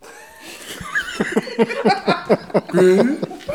ajout des sons enregistrés à l'afk ...
rire-foule_05.wav